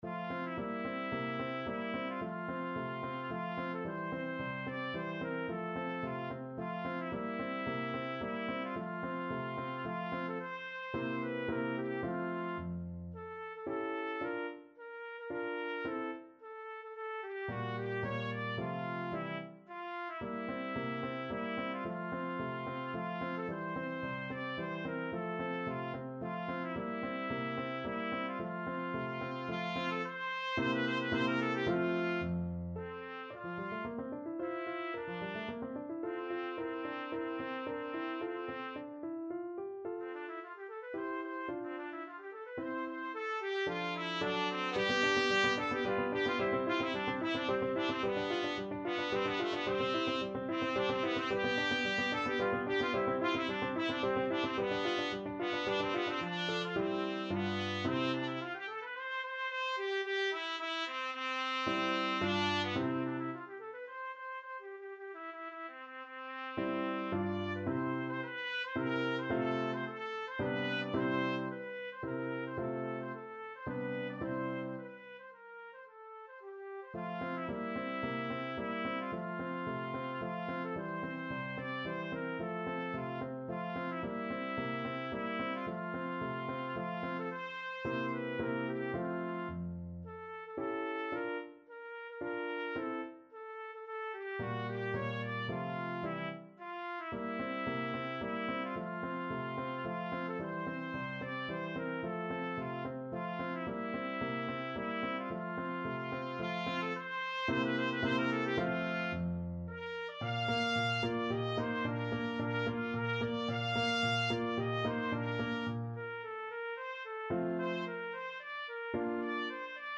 Tempo di Menuetto Trumpet version
3/4 (View more 3/4 Music)
F major (Sounding Pitch) G major (Trumpet in Bb) (View more F major Music for Trumpet )
II: Tempo di Menuetto =110
Trumpet  (View more Intermediate Trumpet Music)
Classical (View more Classical Trumpet Music)
beethoven_op49_no2_mvt2_TPT.mp3